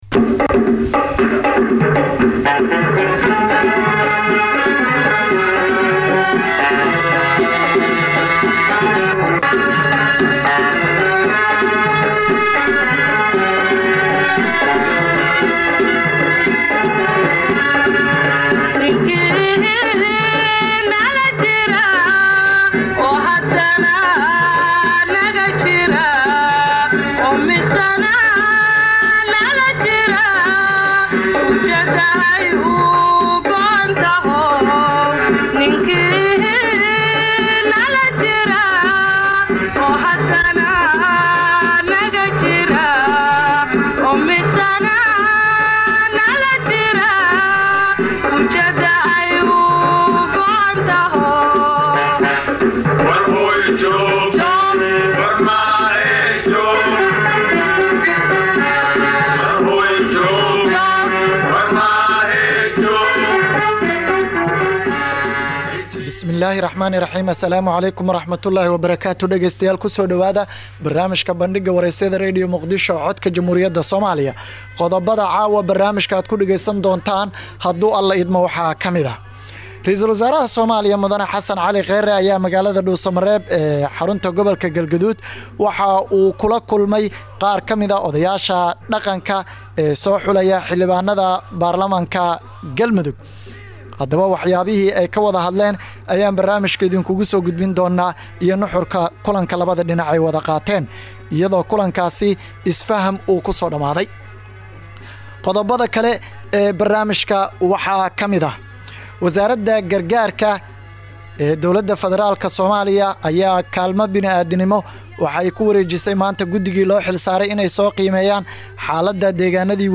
Dhegeyso: Barnaamijka Bandhiga Wareysiyada Radio Muqdisho